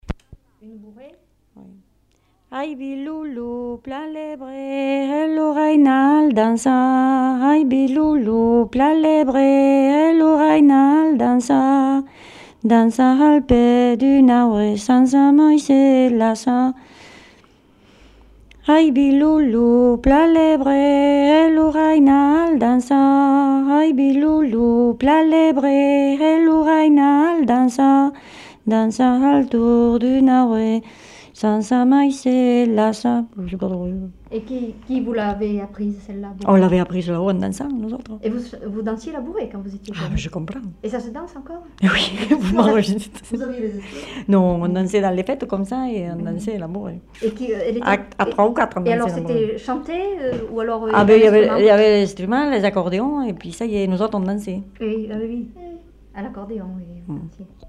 Lieu : Lodève
Genre : chant
Effectif : 1
Type de voix : voix de femme
Production du son : chanté
Danse : bourrée